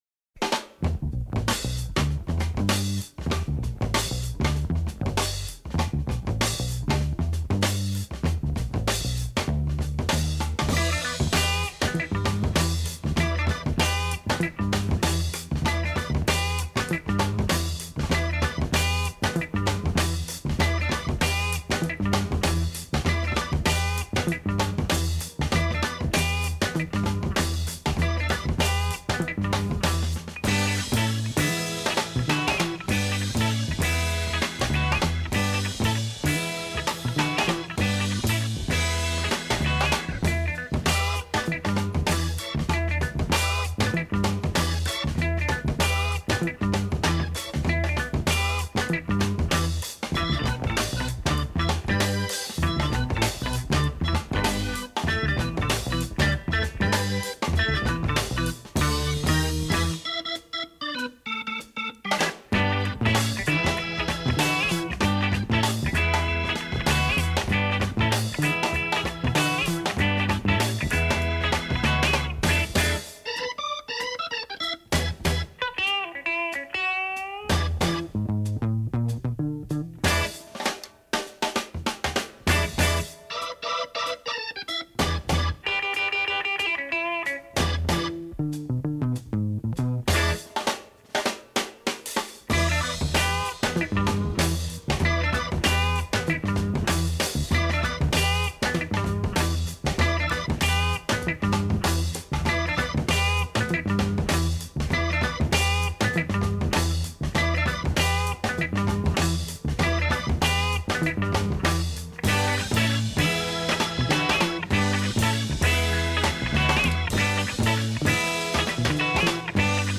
Category: Funk